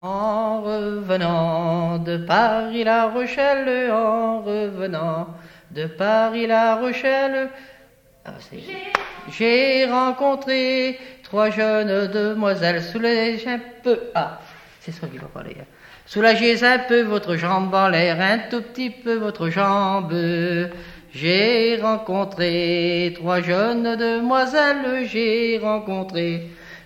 danse : ronde : demi-rond
chansons traditionnelles
Pièce musicale inédite